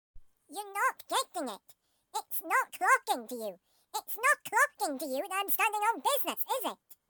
Whoa, he sounds angry 😬